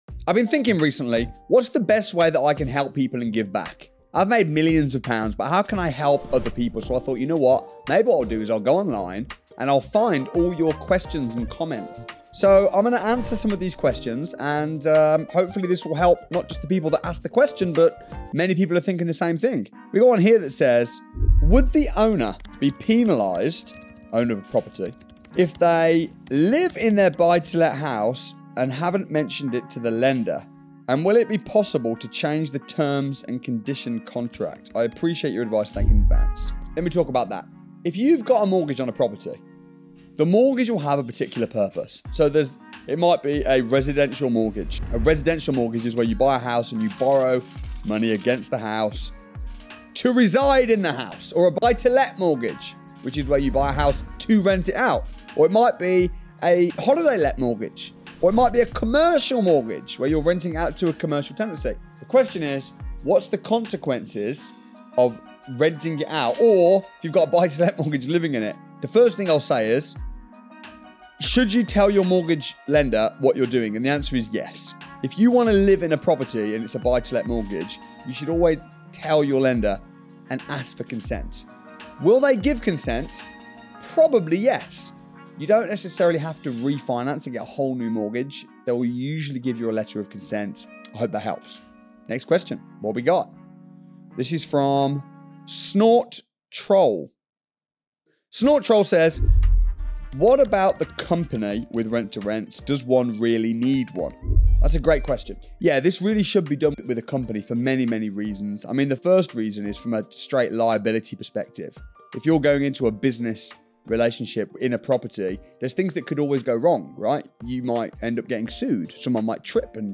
Should I Transfer My Property to a Company? | Q&A with Property Multi-Millionaire